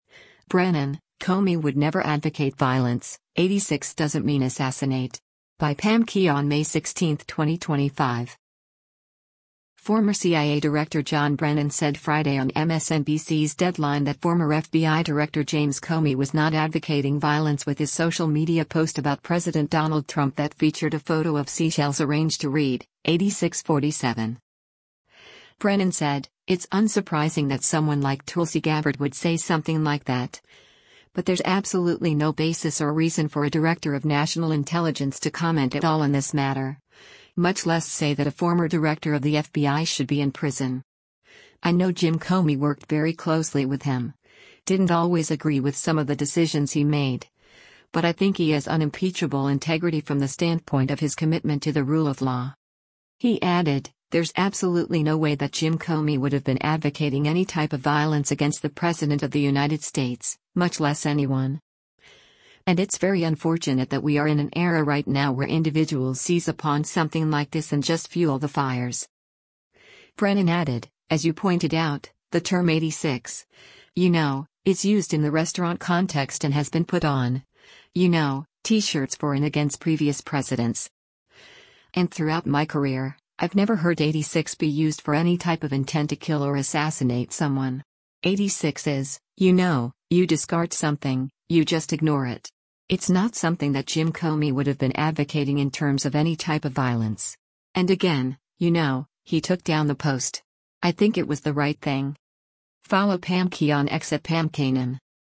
Former CIA Director John Brennan said Friday on MSNBC’s “Deadline” that former FBI Director James Comey was not advocating violence with his social media post about President Donald Trump that featured a photo of seashells arranged to read, “86 47.”